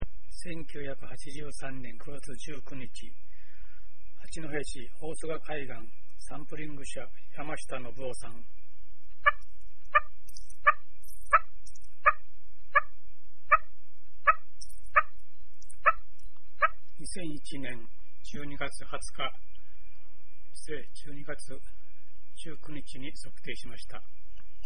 1983年9月19日大須賀海岸の鳴り砂です
ちゃんと鳴いた。